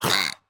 Minecraft Version Minecraft Version snapshot Latest Release | Latest Snapshot snapshot / assets / minecraft / sounds / mob / dolphin / attack2.ogg Compare With Compare With Latest Release | Latest Snapshot